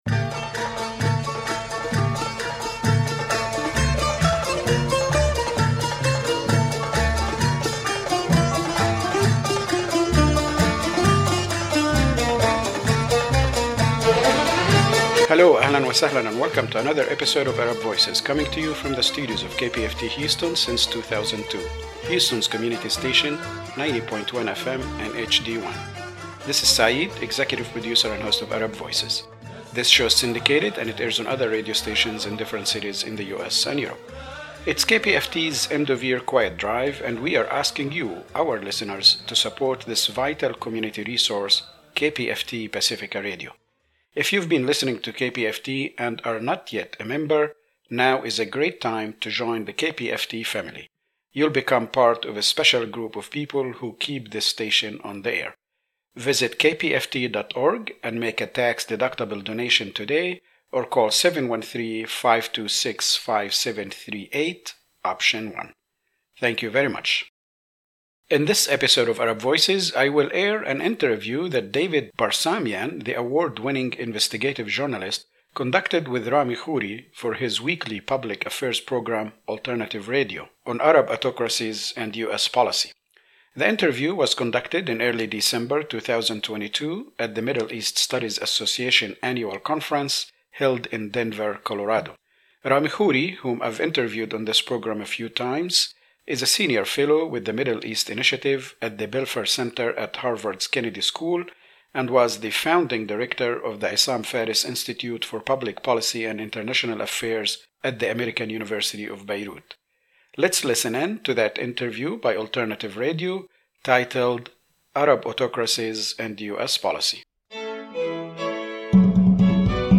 The lecture was delivered at the United Nations in November 2017 by Professor Rashid Khalidi, organized by the United Nations Committee on the Exercise of the Inalienable Rights of the Palestinian People.